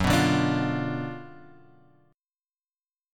F#M7sus4#5 chord {2 2 0 x 3 1} chord